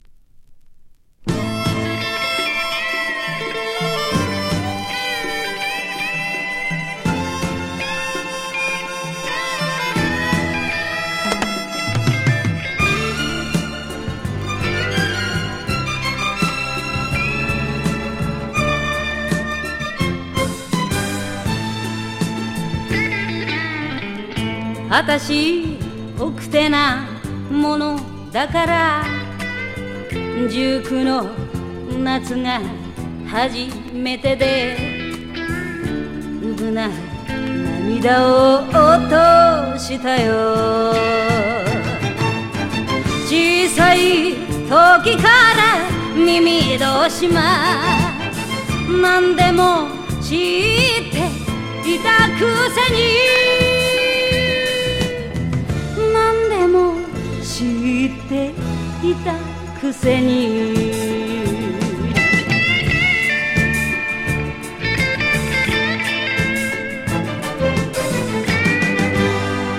スリリング演歌グルーヴ